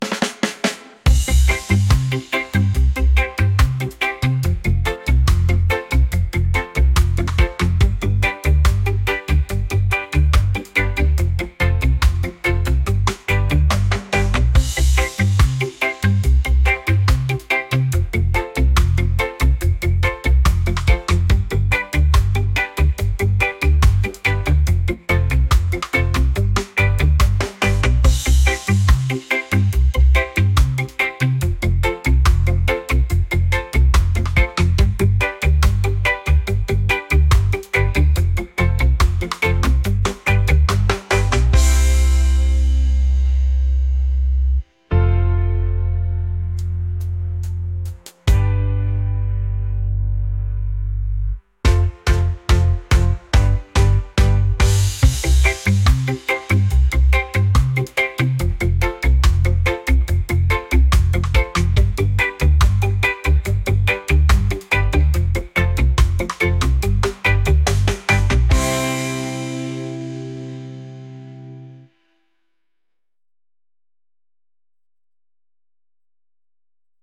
reggae | funk